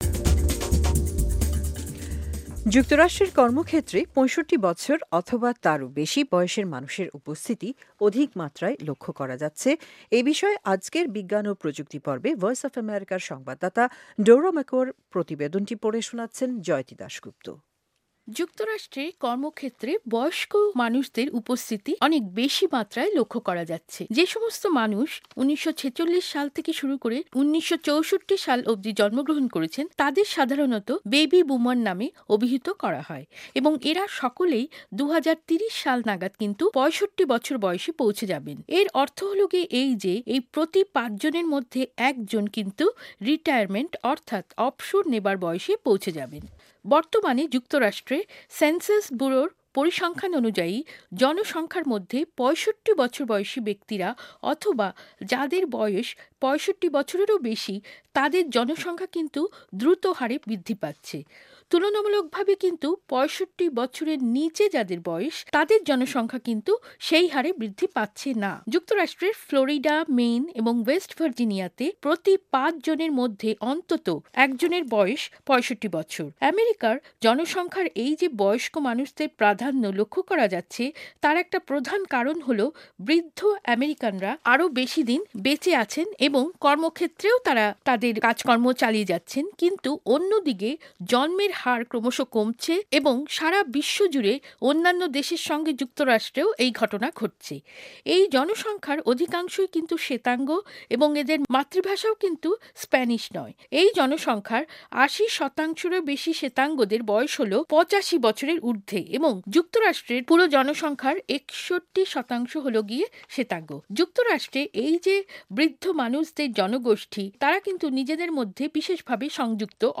বিজ্ঞান ও প্রযুক্তি পর্বে প্রতিবেদনটি পড়ে শোনাচ্ছেন